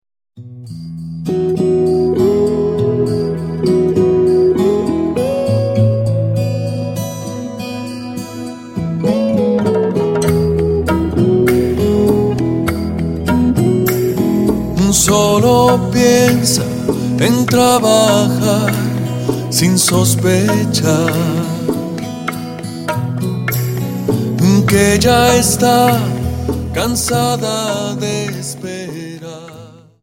Rumba 25 Song